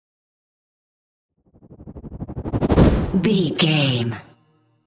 Whoosh to hit trailer
Sound Effects
futuristic
intense
tension
woosh to hit